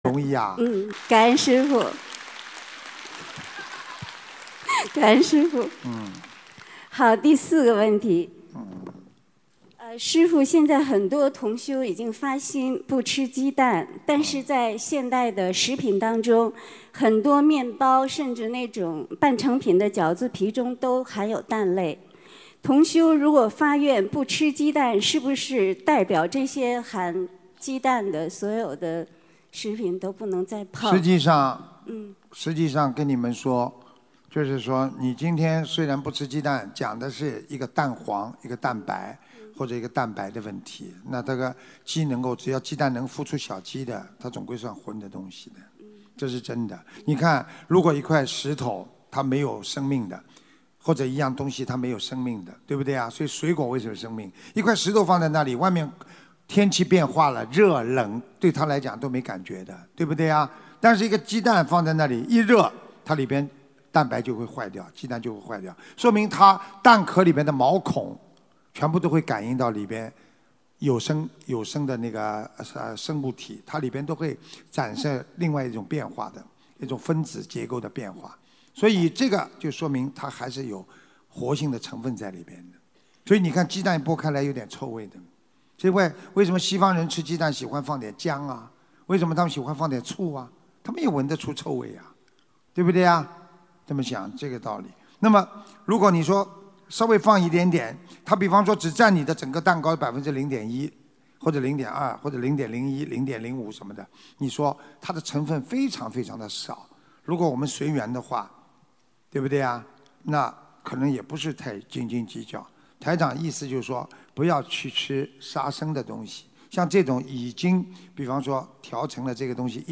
Pertanyaan di Seminar Dharma
Pertanyaan pada Acara Pertemuan Umat Buddhis Sedunia di Sydney – Australia, 11 Januari 2020